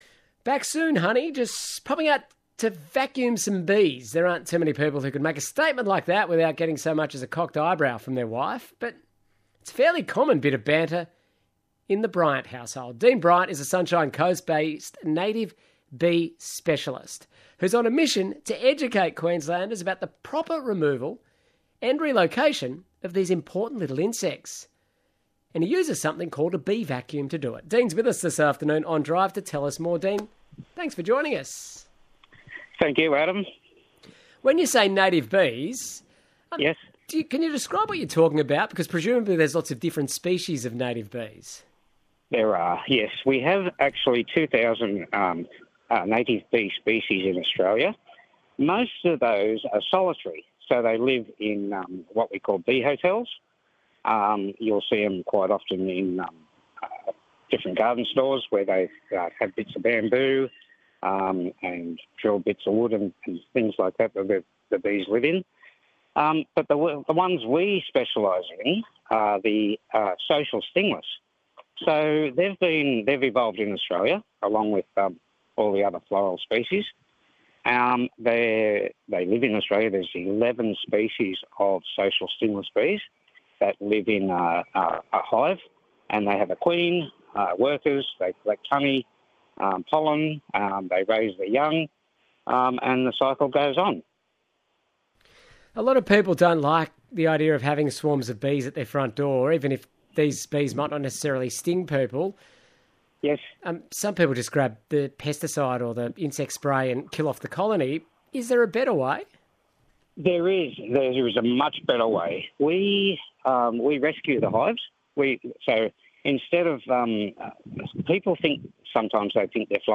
ABC North Queensland Drive - Interview